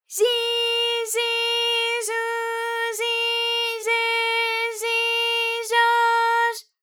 ALYS-DB-001-JPN - First Japanese UTAU vocal library of ALYS.